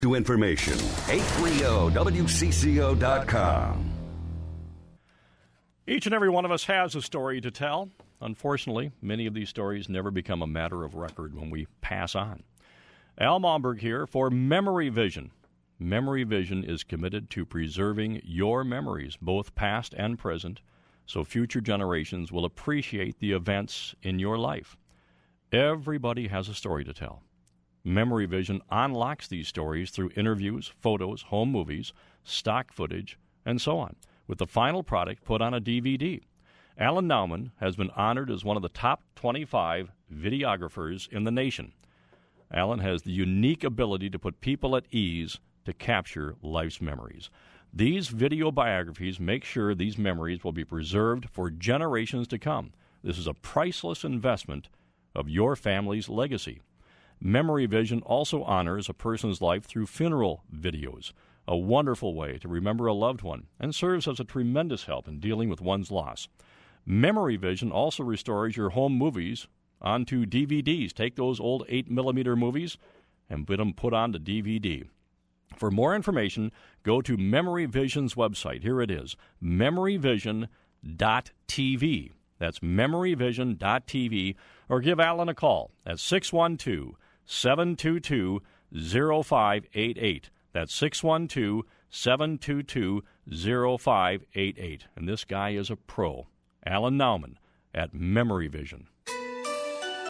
WCCO RadioAs heard on WCCO Radio